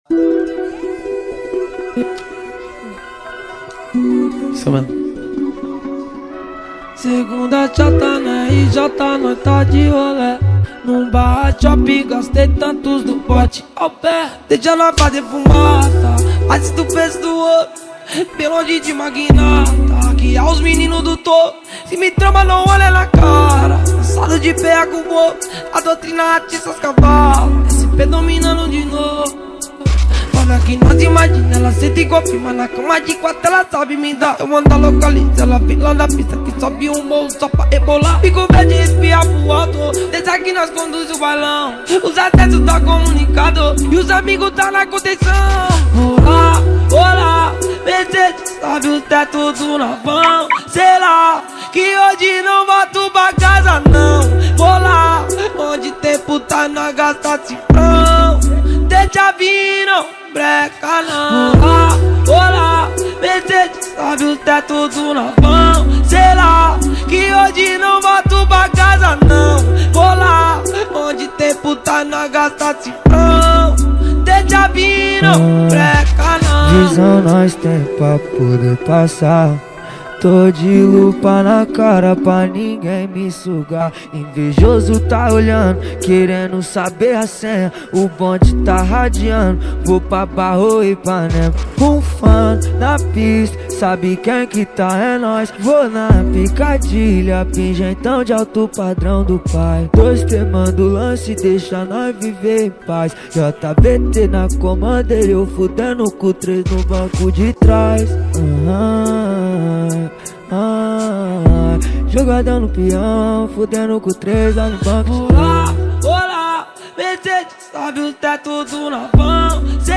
Gênero: MPB